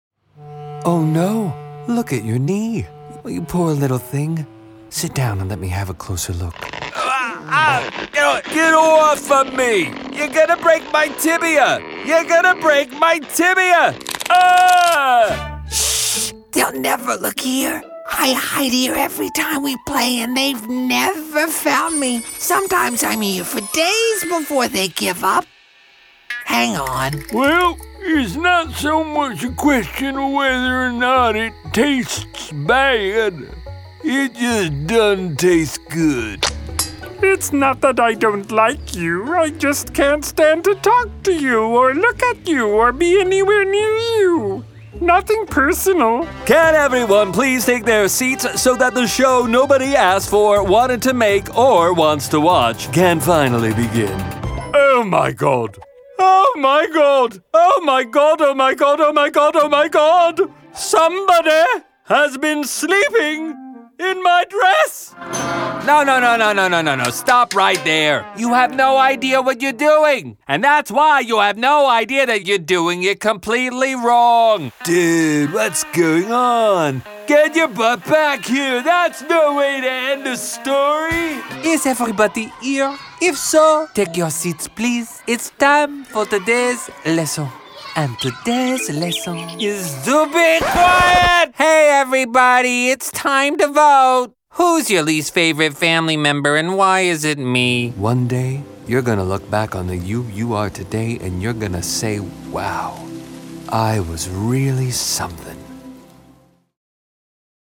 Animation
American, Midwest, Southern States, Texan